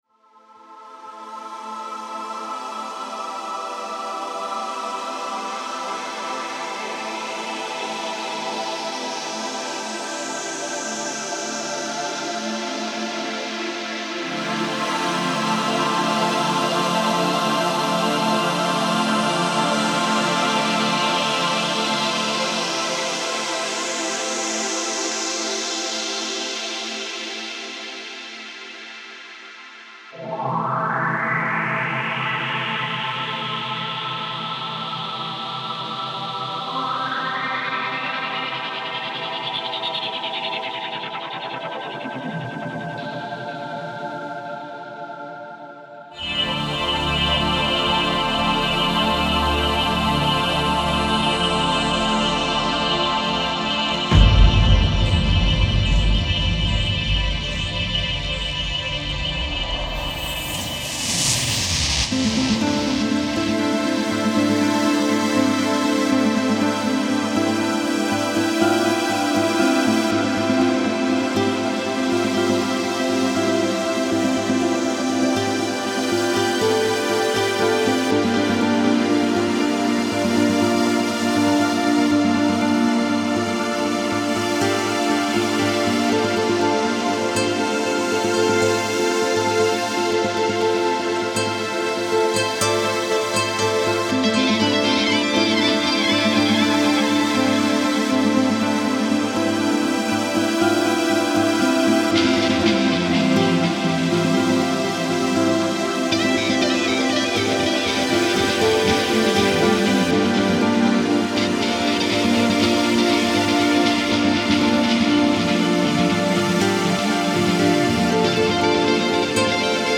Electronic music inspired by space